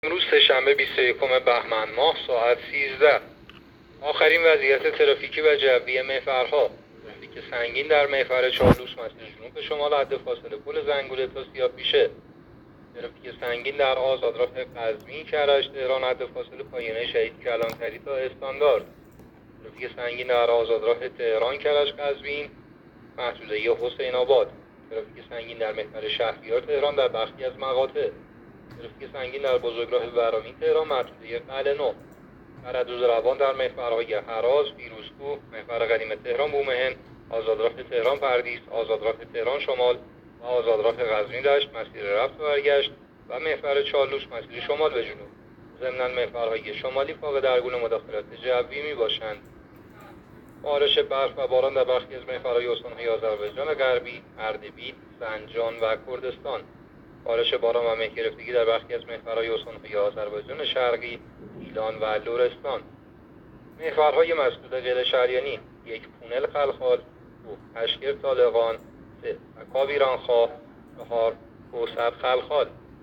گزارش رادیو اینترنتی از آخرین وضعیت ترافیکی جاده‌ها ساعت ۱۳ بیست و یکم بهمن؛